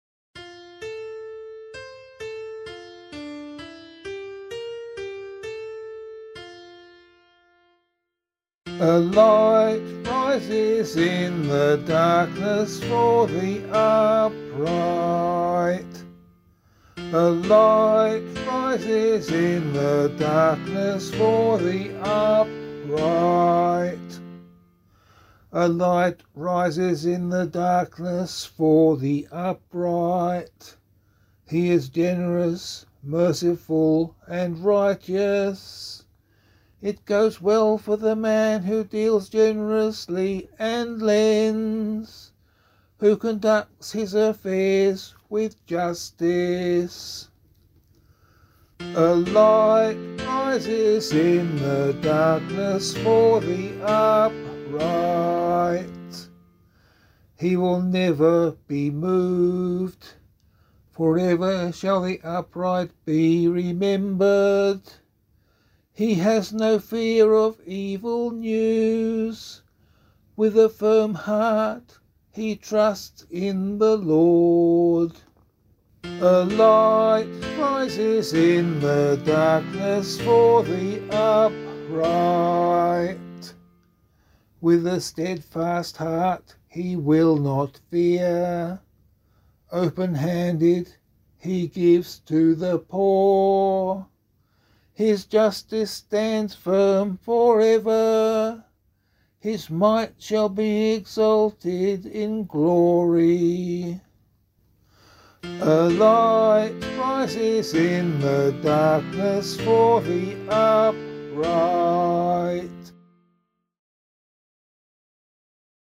039 Ordinary Time 5 Psalm A [APC - LiturgyShare + Meinrad 6] - vocal.mp3